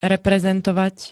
Zvukové nahrávky niektorých slov
ixva-reprezentovat.spx